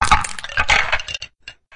PixelPerfectionCE/assets/minecraft/sounds/mob/witherskeleton/death.ogg at mc116